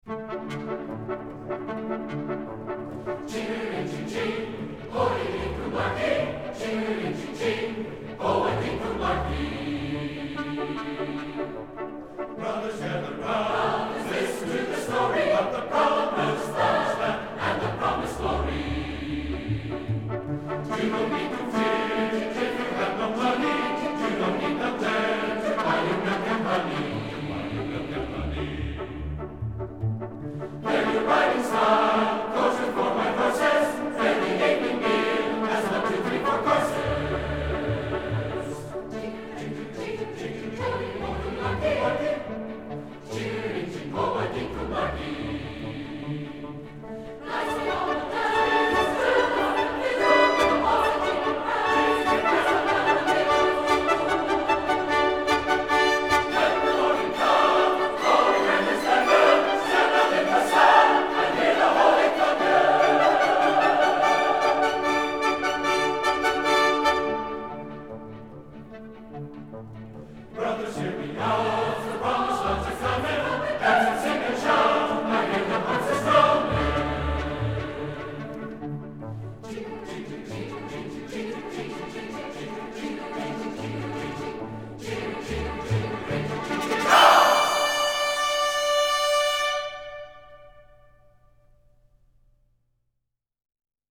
Música vocal